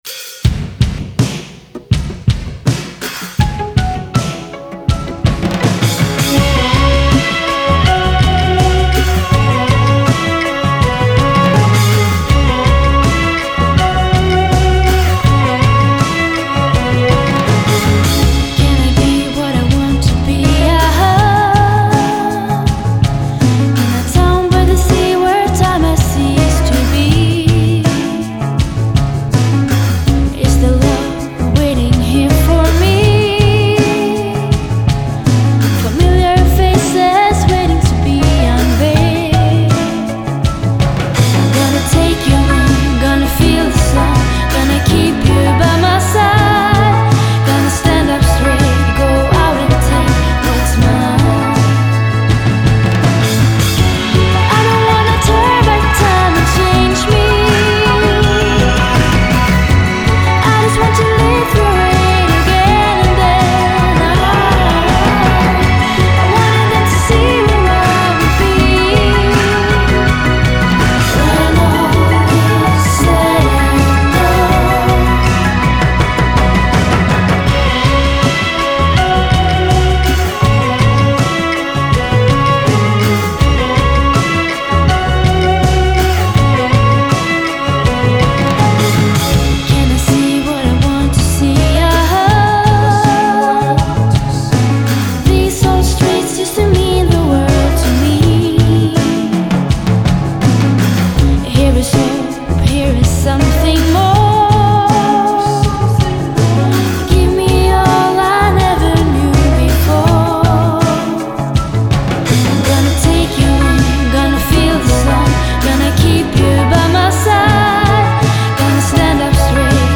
Swedish singer and musician.
Genre: Indie, Pop